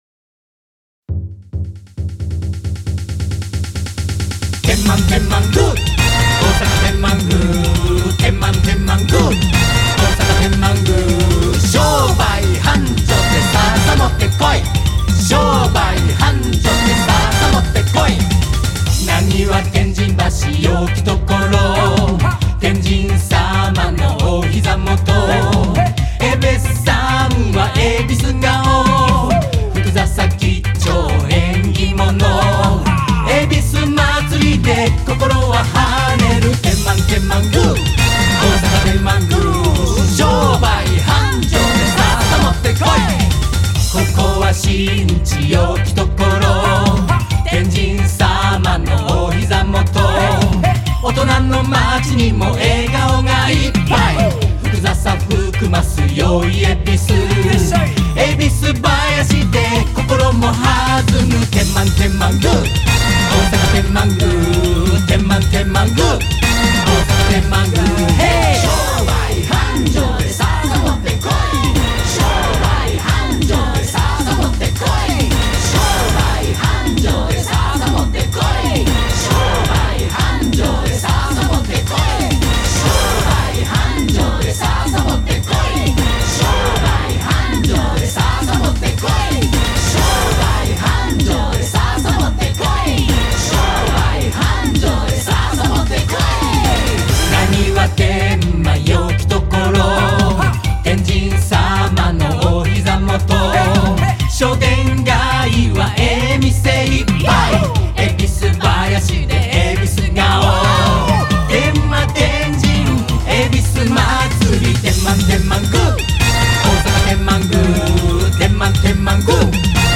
たいへん覚えやすく耳に残るナンバーですので、是非ぜひ皆さんもご家族やご友人・知人にお披露目＆お拡めくださいね。